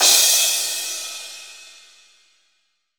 • Big Room Crash E Key 02.wav
Royality free drum crash sound tuned to the E note. Loudest frequency: 6331Hz
big-room-crash-e-key-02-p1u.wav